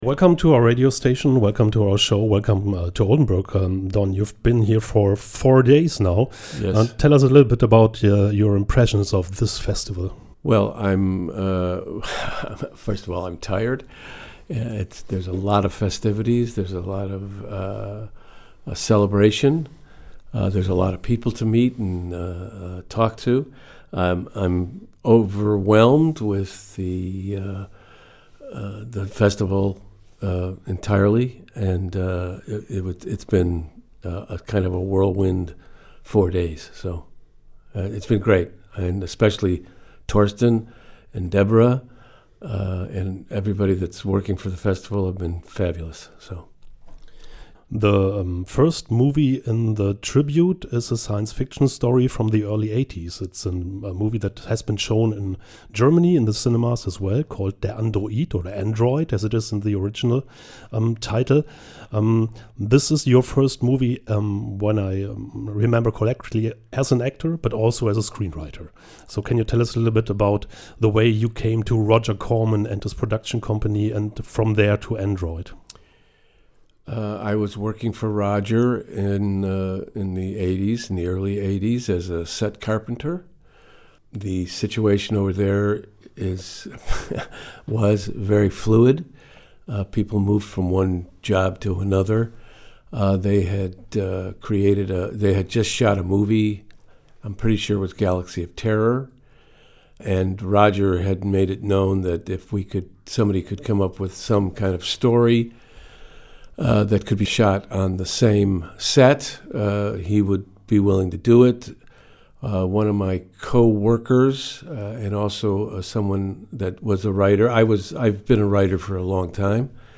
Radiointerview
im Studio von Oldenburg Eins